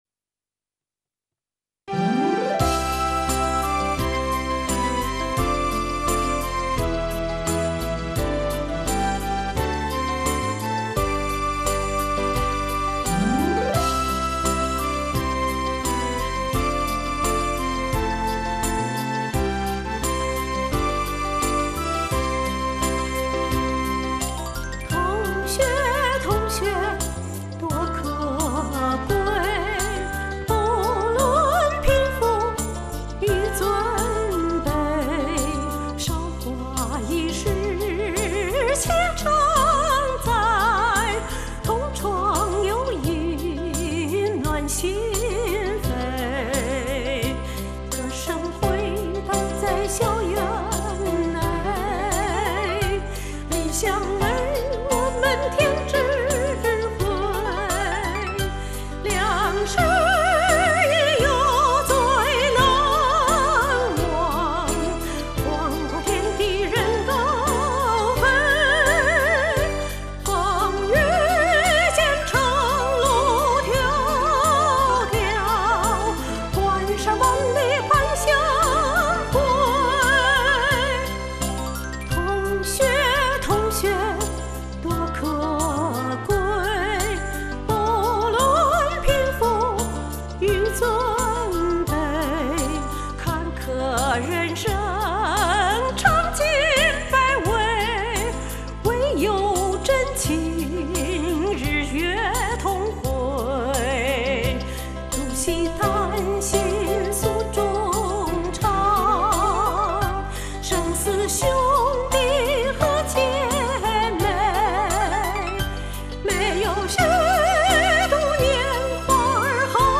那美妙而婉转如云霄的歌声